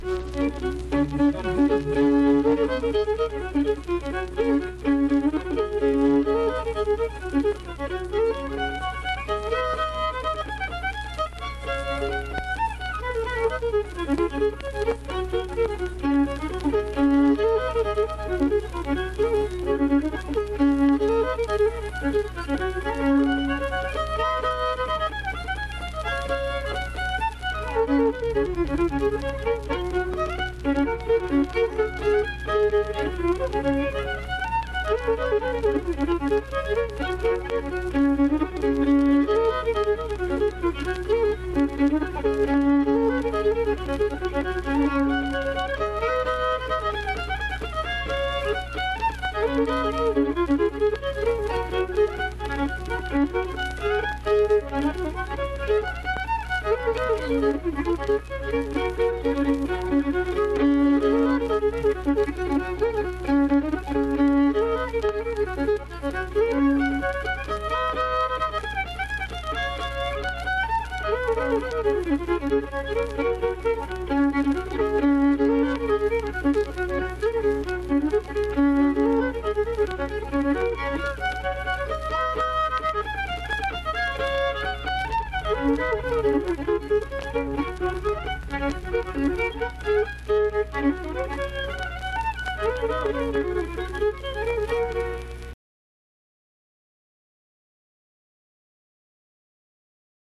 Instrumental fiddle performance.
Instrumental Music
Fiddle
Wood County (W. Va.), Vienna (W. Va.)